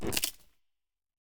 Minecraft Version Minecraft Version snapshot Latest Release | Latest Snapshot snapshot / assets / minecraft / sounds / mob / creaking / creaking_freeze4.ogg Compare With Compare With Latest Release | Latest Snapshot
creaking_freeze4.ogg